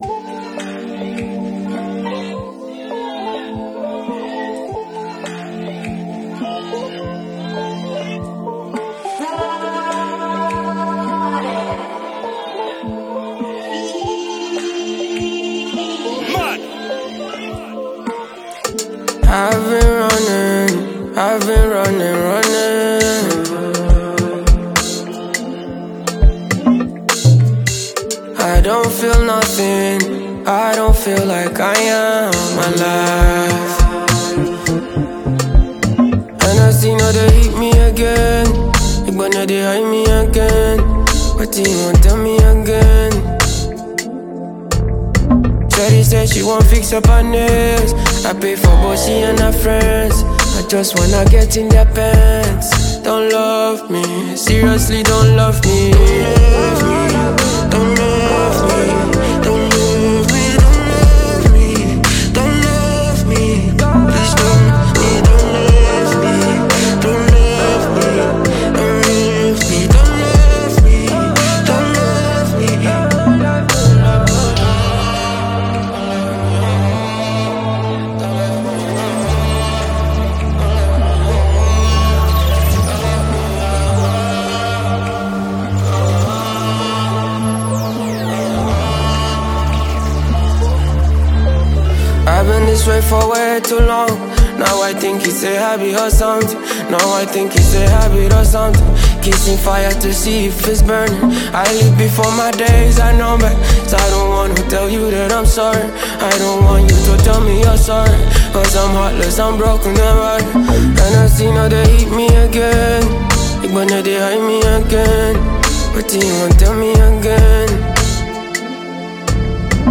Afrobeats
Over a smooth but melancholic instrumental
soft vocals with haunting lyrics